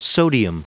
Prononciation du mot sodium en anglais (fichier audio)
Prononciation du mot : sodium